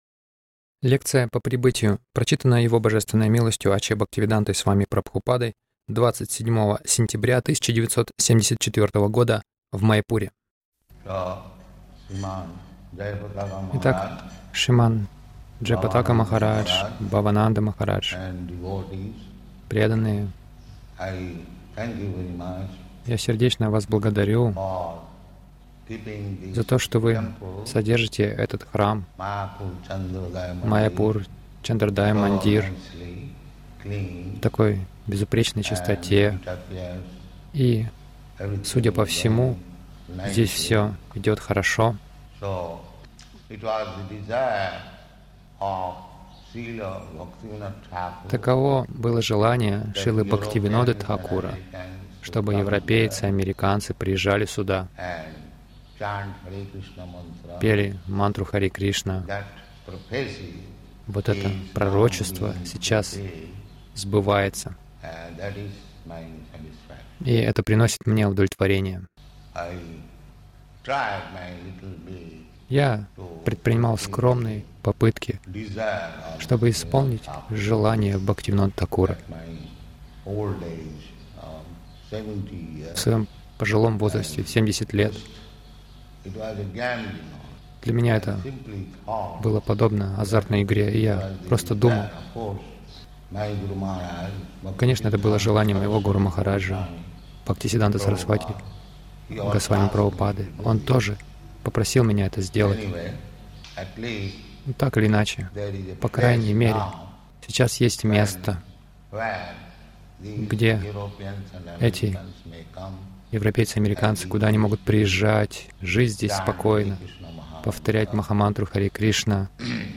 Лекция — Инфекция материальными гунами
Милость Прабхупады Аудиолекции и книги 27.09.1974 Лекции | Маяпур Лекция — Инфекция материальными гунами Загрузка...